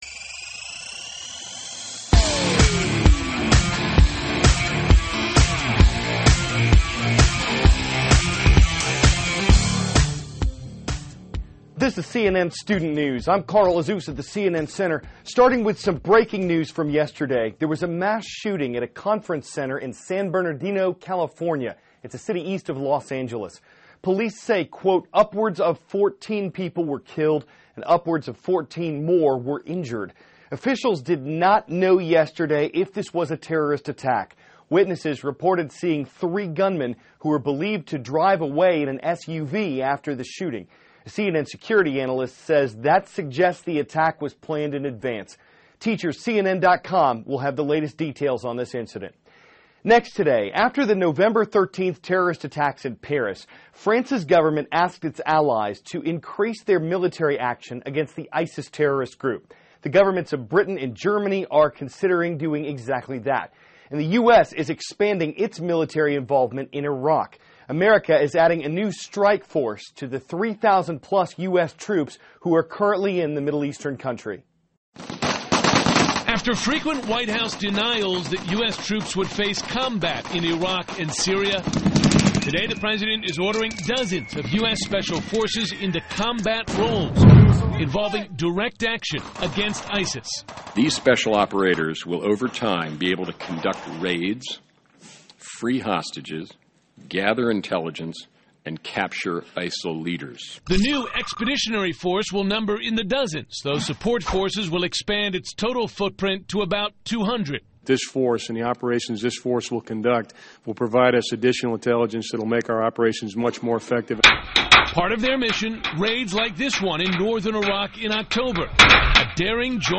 *** CARL AZUZ, CNN STUDENT NEWS ANCHOR: This is CNN STUDENT NEWS.